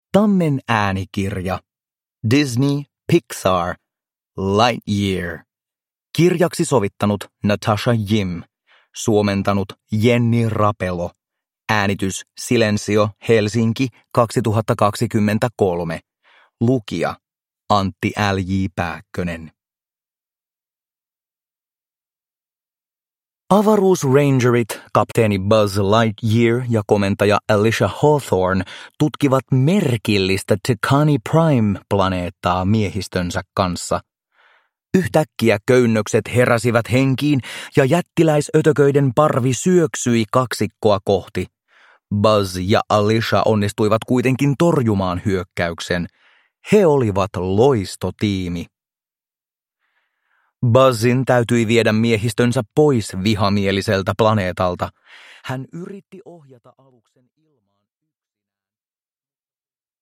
Disney Pixar. Lightyear – Ljudbok – Laddas ner